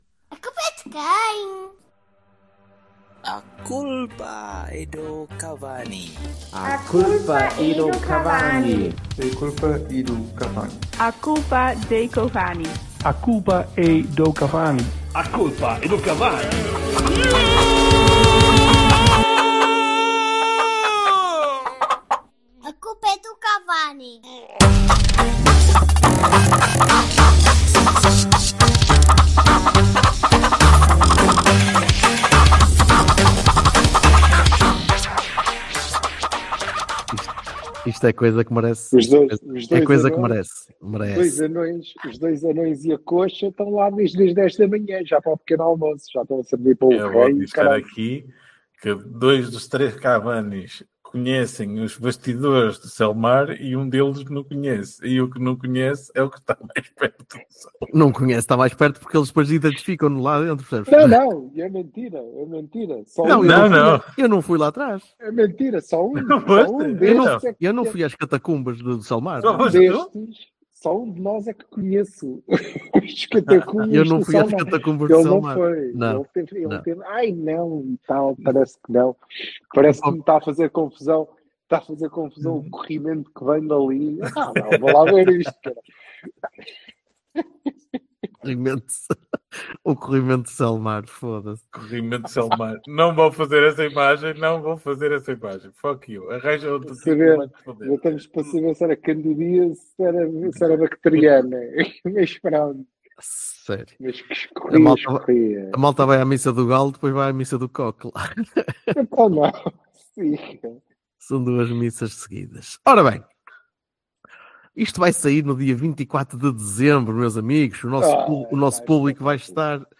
Três portistas.
Um microfone reles.